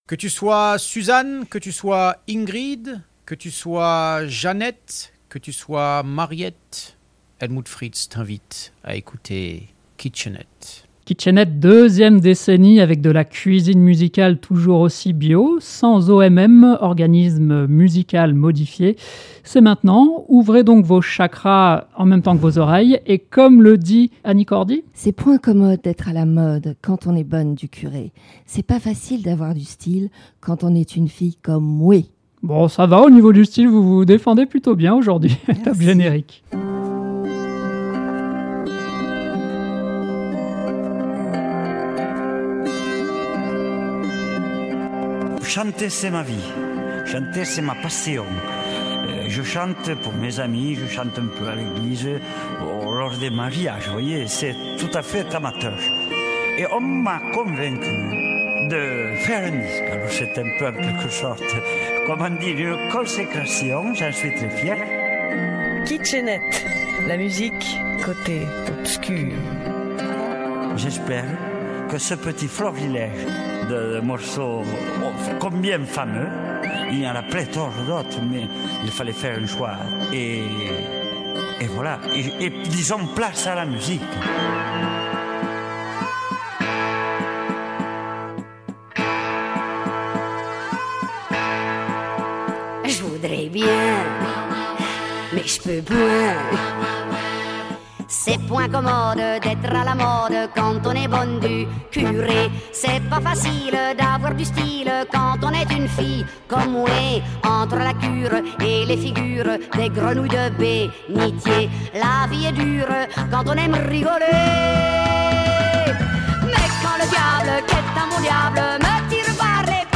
Le direct du 24 octobre 2020